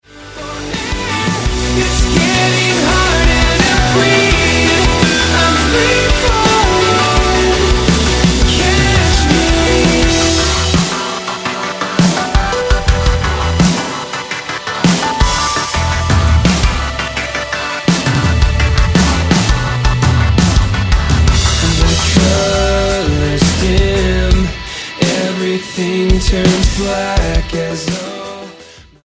pop rock band
Style: Rock